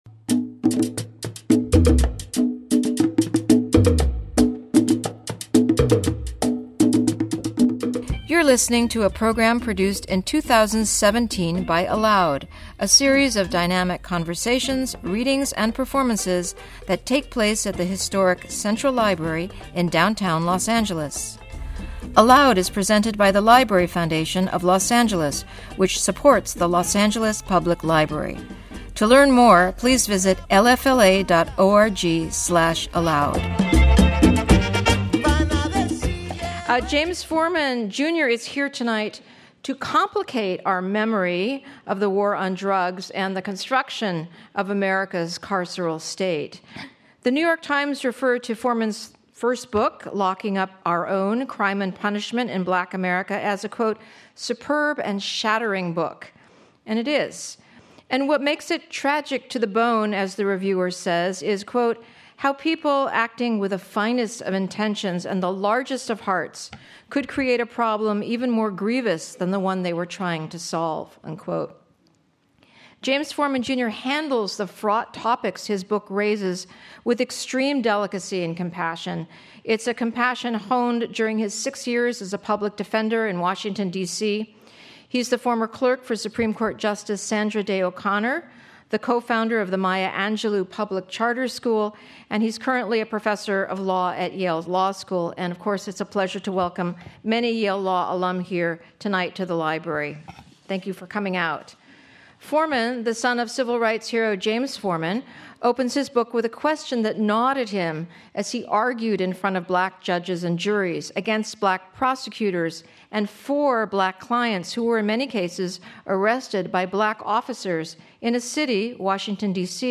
email James Forman, Jr. In Conversation With Robin D.G. Kelley Wednesday, November 29, 2017 01:19:34 ALOUD Listen: play pause stop / aloudlocking-our-own.mp3 Listen Download this episode Episode Summary Why has our society become so punitive?